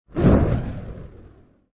descargar sonido mp3 rafaga fuego